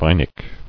[vi·nic]